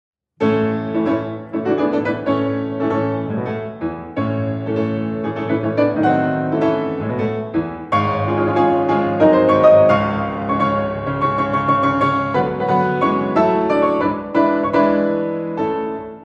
リズミカルな、とんっ、とんっ、とんっ、というある程度規則的なテンポを掴むことができたでしょうか。
・拍子記号は４分の３拍子。４分音符（♩）が１小節に３つ
・演奏の速さは♩=９２くらい（ざっくり、アレグロの速度が１２０〜１６０あたりで示される数値との比較では少し遅め）
軽快な「速く、愉快で、いきいきと」を感じることができます。